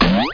00096_Sound_Bloop2
1 channel